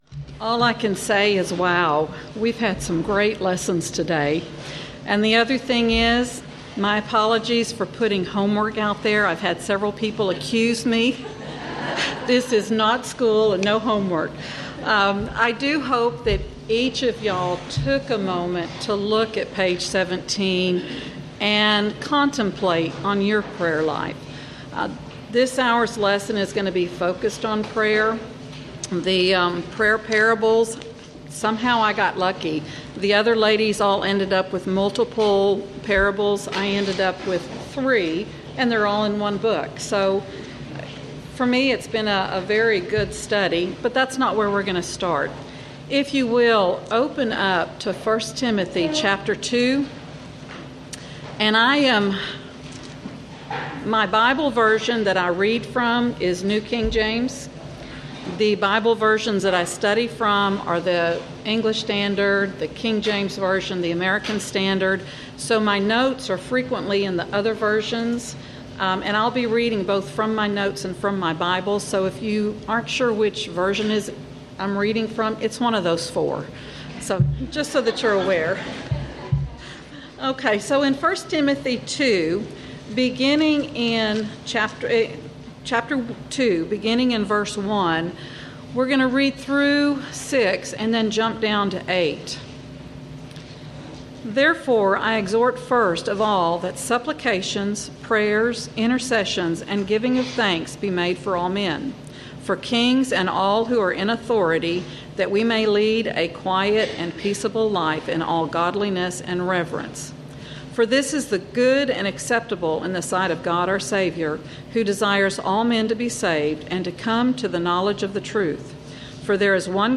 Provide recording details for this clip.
Event: 9th Annual Texas Ladies in Christ Retreat